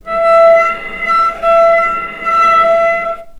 cello / sul-ponticello
vc_sp-E5-mf.AIF